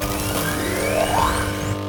charge1.ogg